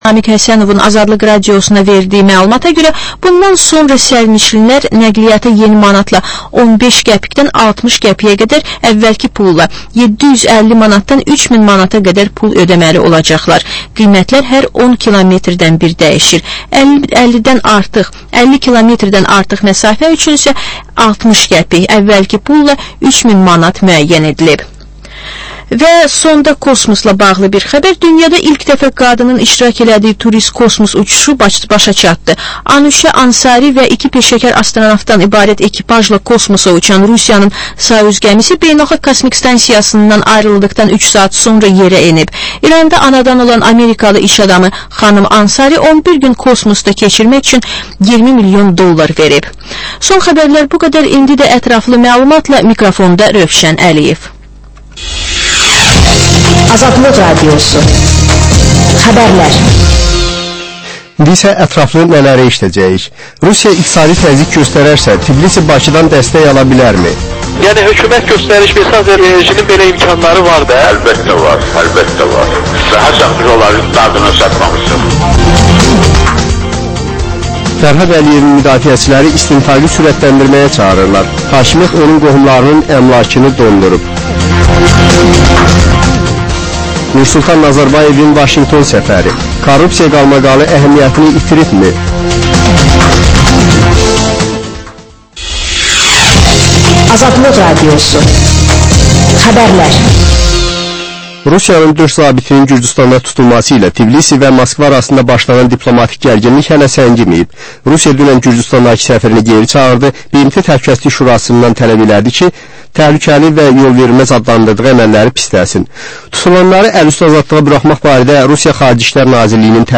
Reportaj, müsahibə, təhlil